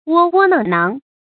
窝窝囊囊 wō wō nāng nāng
窝窝囊囊发音